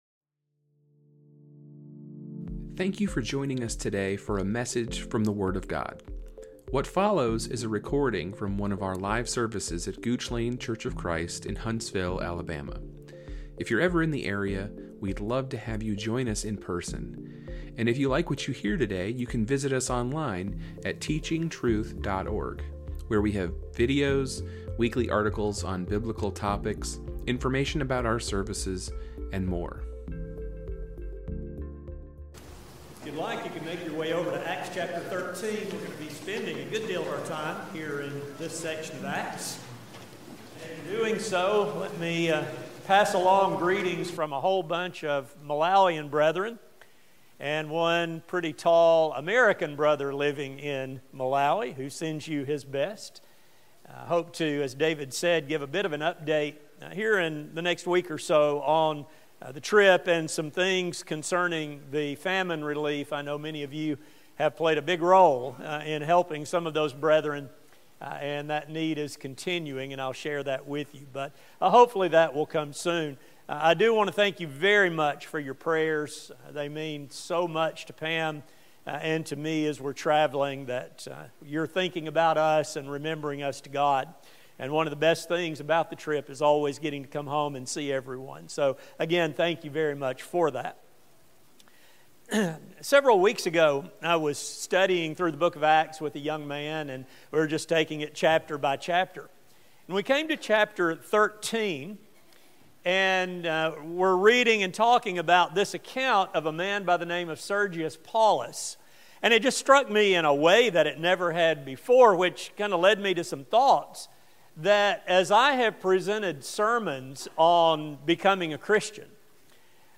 A sermon